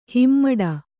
♪ himmaḍa